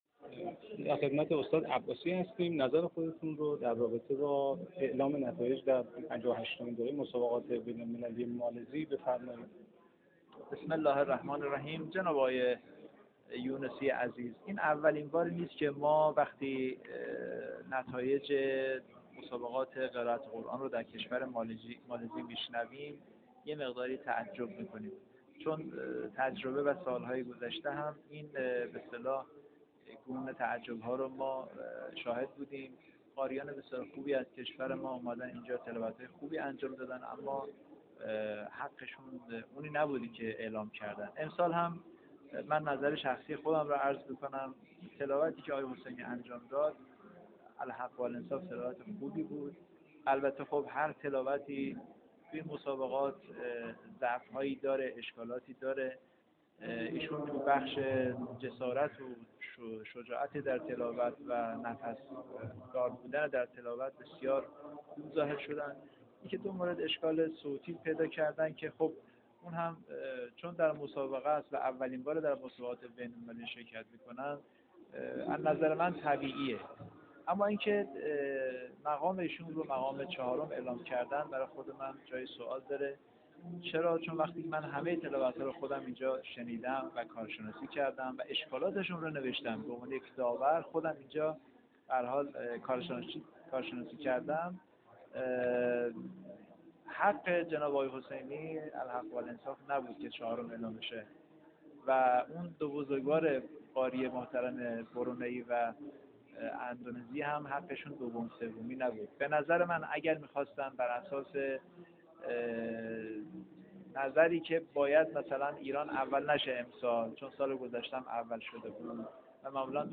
در گفت‌وگو با خبرنگار اعزامی خبرگزاری بین‌المللی قرآن(ایکنا)